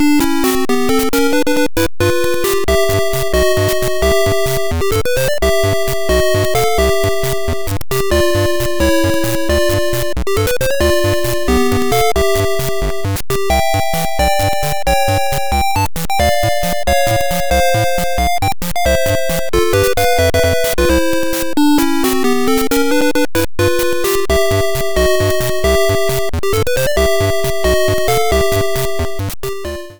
Key C major
Sharp X1 version